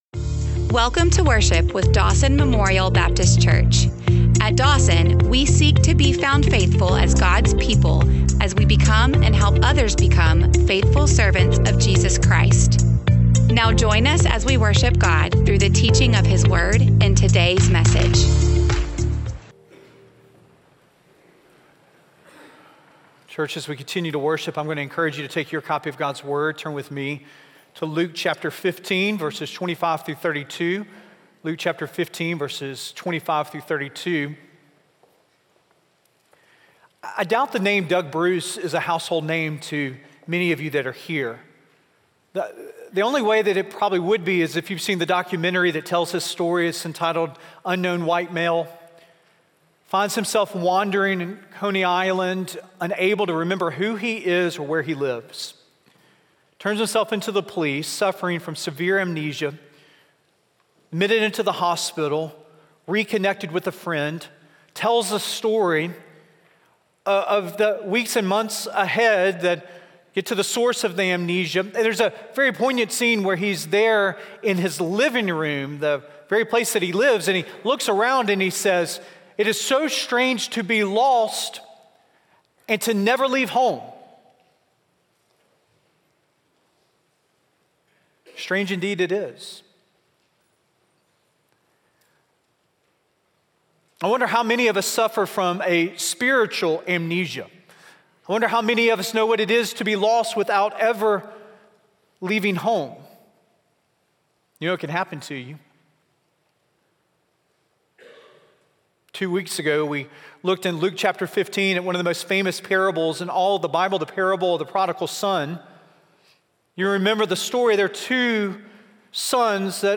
Teaching sermons presented during Sunday morning worship experiences with the Dawson Family of Faith, Birmingham, Alabama.